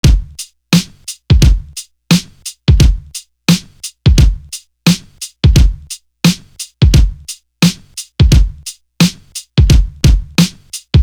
Bumpy Drum.wav